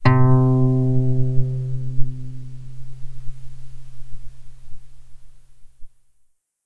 acoustic-guitar
C3_pp.mp3